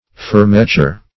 Search Result for " fermeture" : The Collaborative International Dictionary of English v.0.48: Fermeture \Fer"me*ture\, n. [F., fr. fermer to close.] (Mil.) The mechanism for closing the breech of a breech-loading firearm, in artillery consisting principally of the breechblock, obturator, and carrier ring.